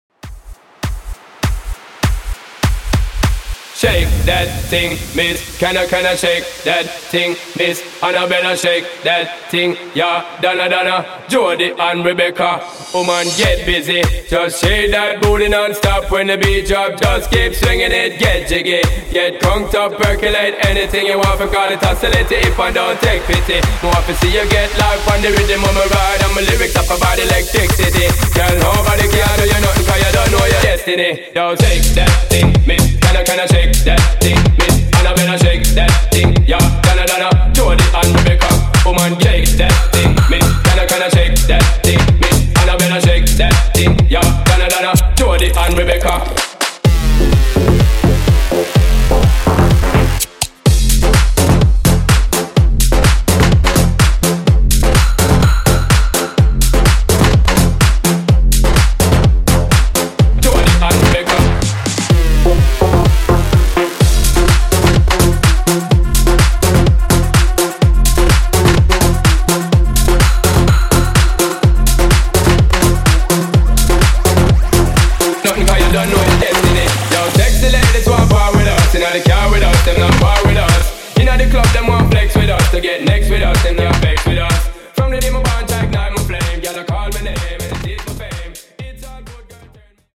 Acapella Intro Trans)Date Added